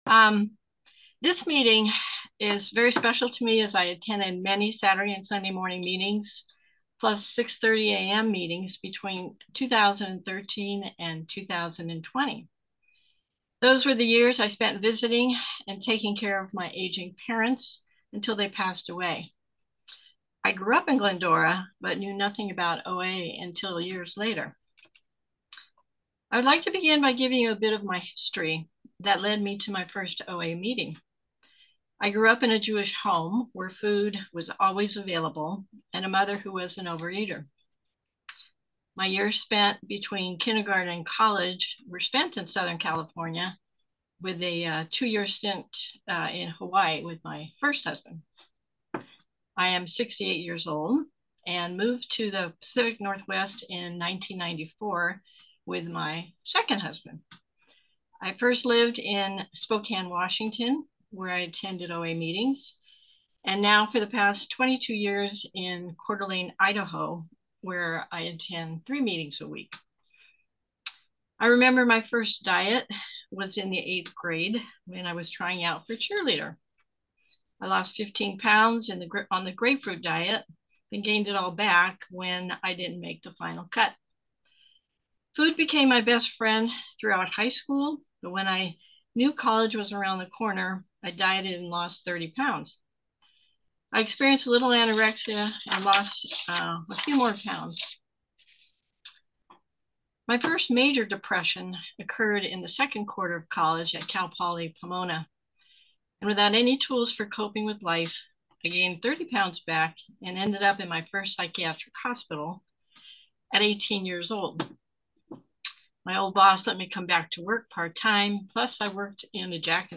Members of OA SGVIE share their experience strength and hope.